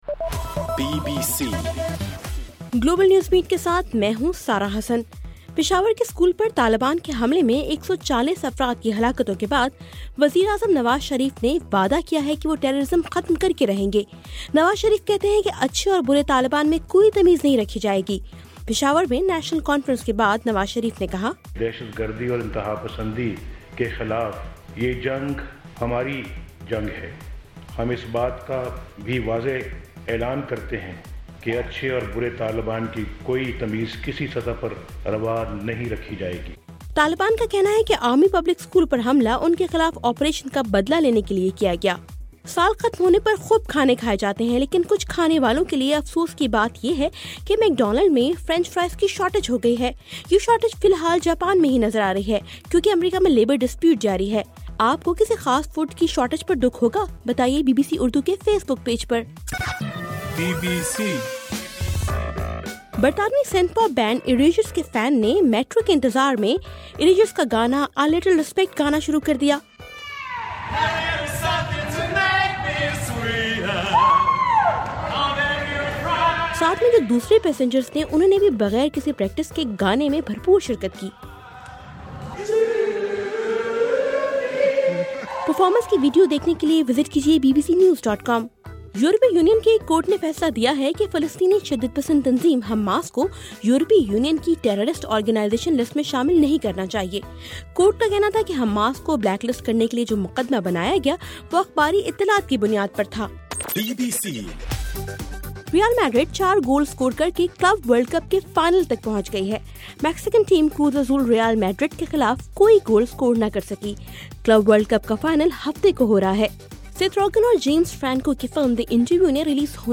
دسمبر 17: رات 10 بجے کا گلوبل نیوز بیٹ بُلیٹن